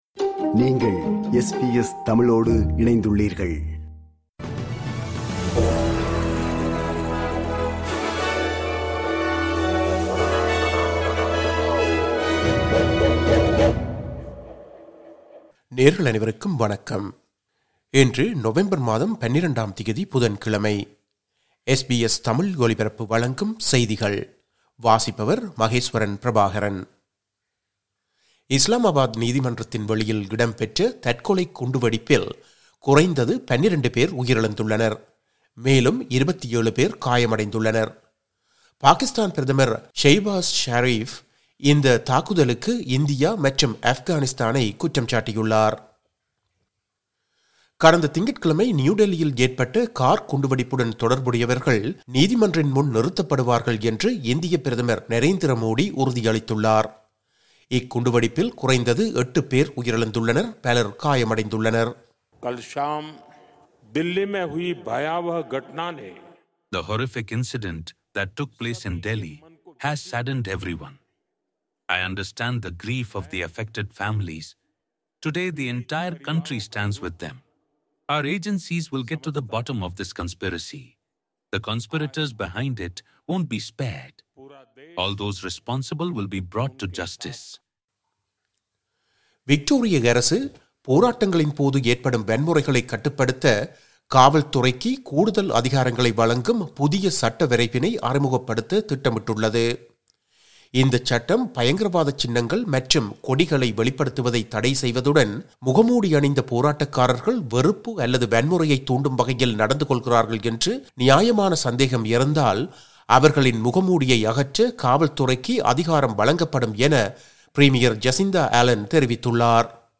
இன்றைய செய்திகள்: 12 நவம்பர் 2025 புதன்கிழமை